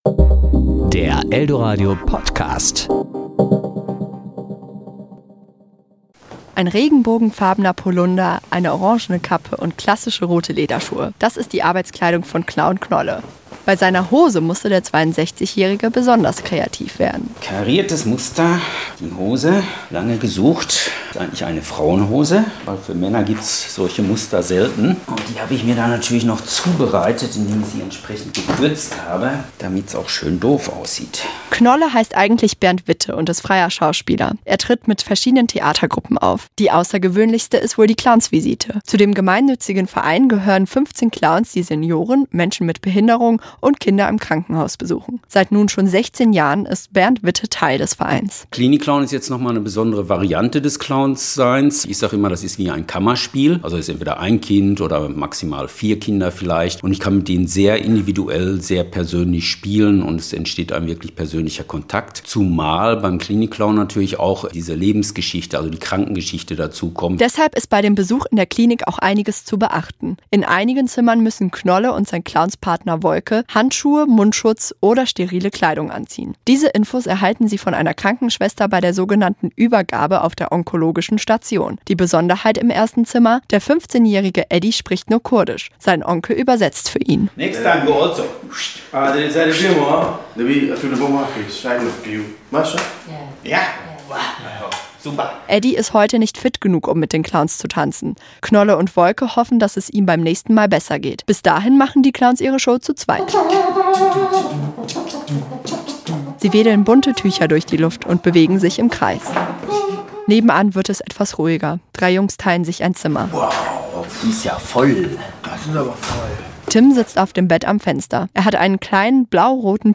Beiträge  Ressort: Wort  Sendung